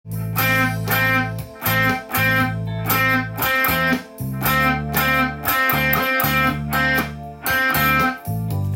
また　伸ばしたり切ったり連続で弾いたりして
１音でそれらしく弾いていくと少しカッコよくなります。